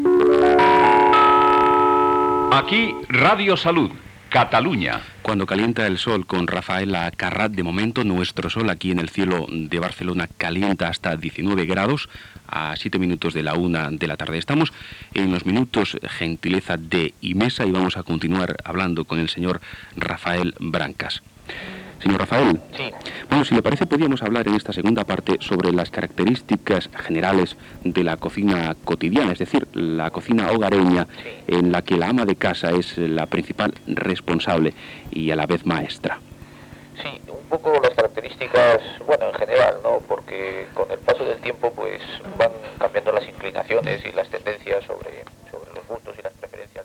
e77068ee6f363a87c260daafbdb618b6c9d8fb0b.mp3 Títol Radio Salud Emissora Radio Salud Titularitat Privada local Descripció Indicatiu, hora, temperatura, espai patrocinat sobre cuina.
Banda FM